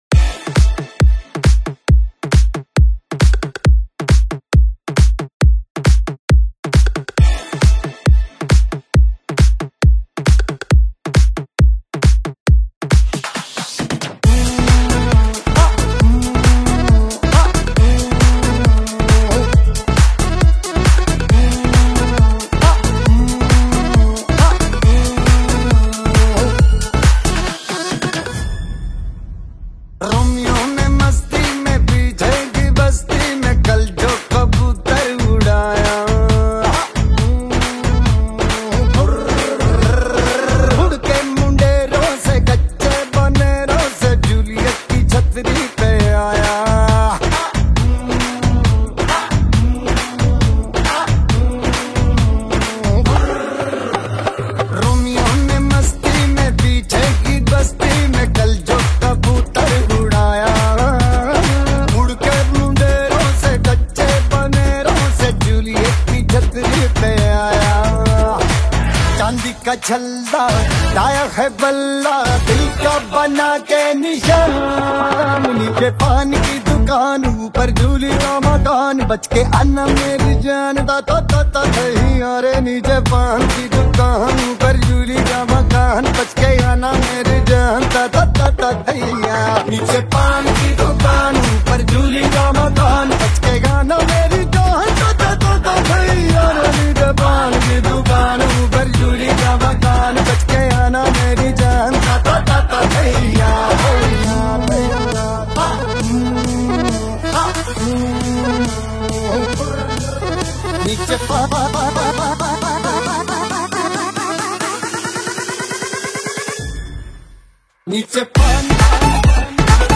high-energy Bollywood club mix
• Mix Type: Club Mix / High Bass
• Category: Bollywood DJ Remix
It offers high bass, clean mixing, and a powerful club vibe.